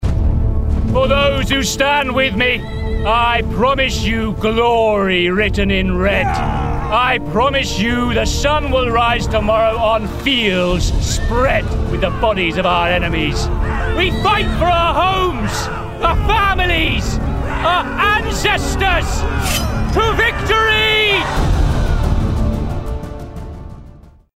20/30's RP/London, Confident/Natural/Direct